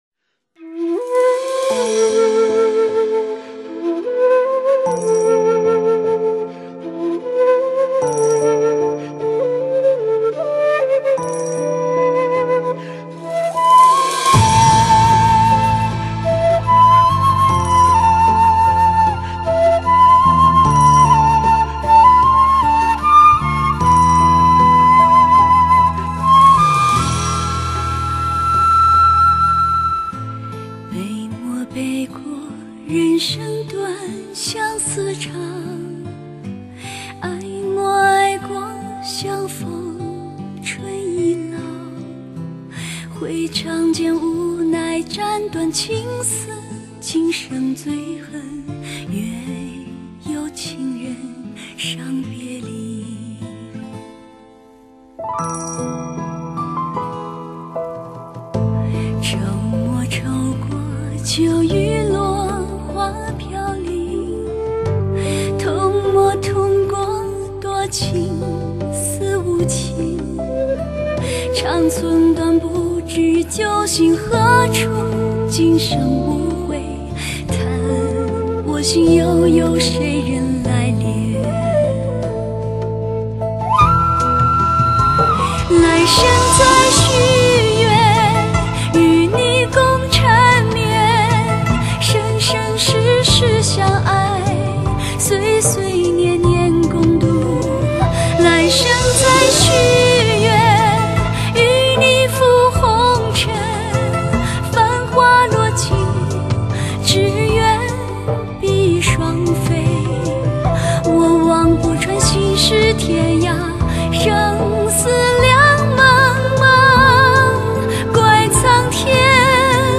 柔情的攻势,唯美的传神 感伤的触动......